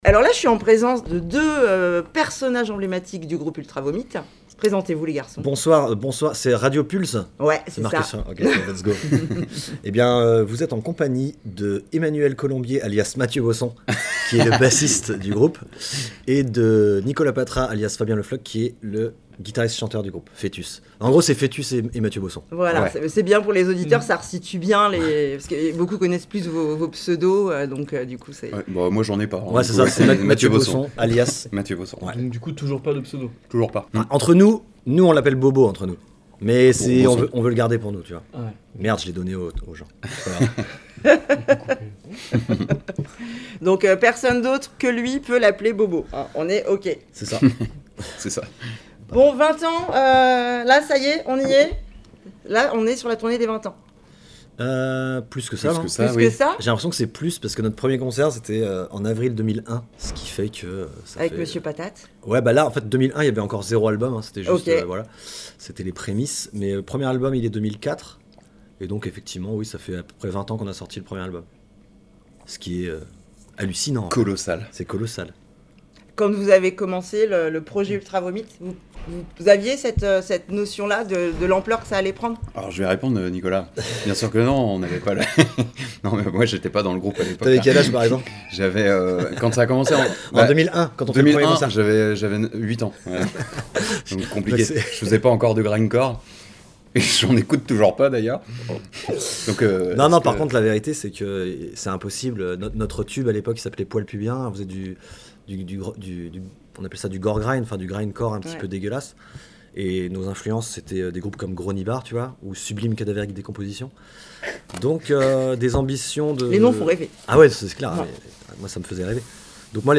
Une interview sincère, drôle et sans filtre, à l’image du groupe. métal